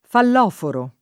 [ fall 0 foro ]